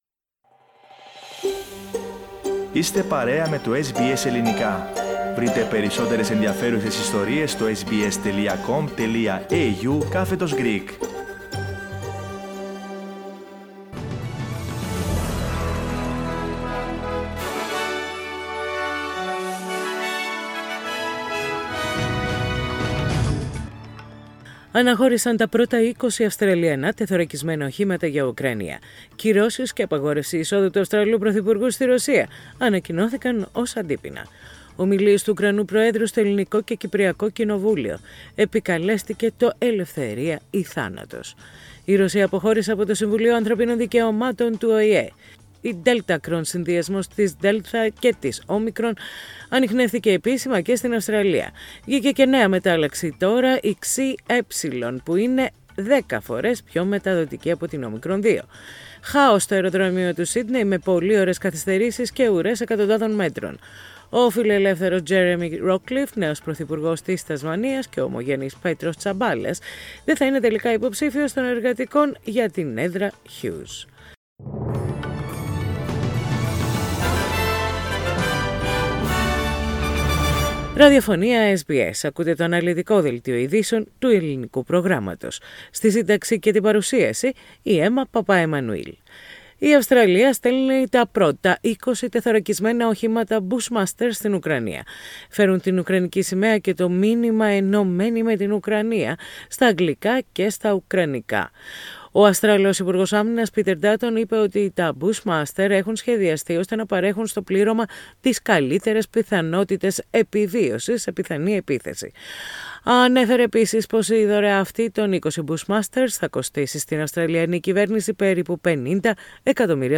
Δελτίο Ειδήσεων - Παρασκευή 8.4.22
News in Greek.